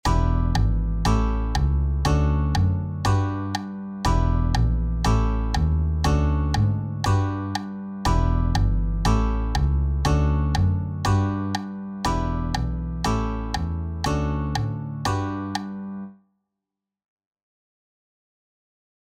Walkingbass - Crashkurs - Teil 1 GT-LT.mp3